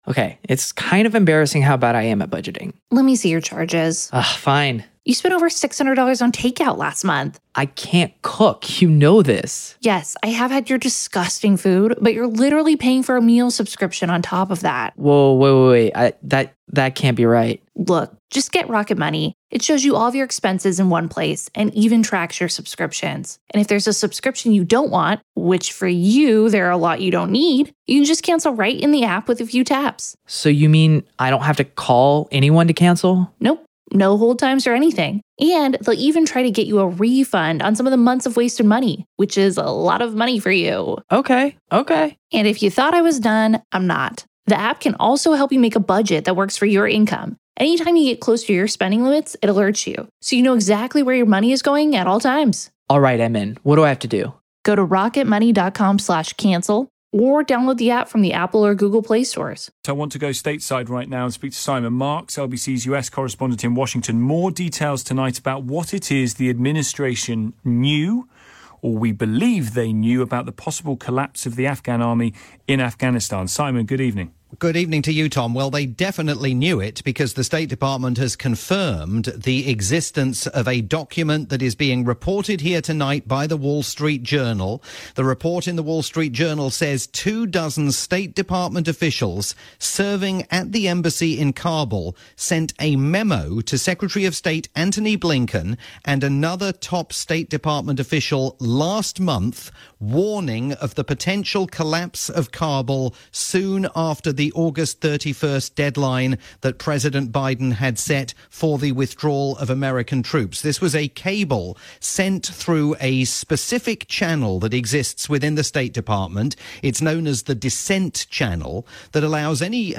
breaking news update
late night programme on the UK's LBC